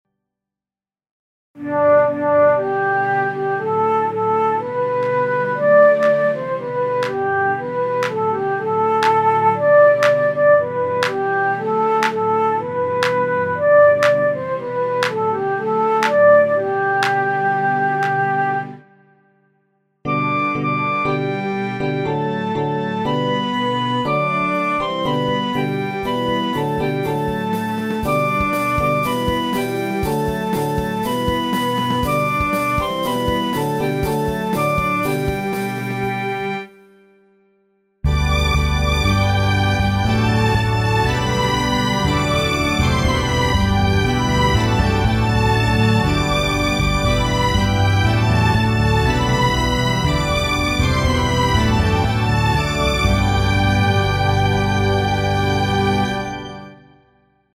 Keyboard G-Dur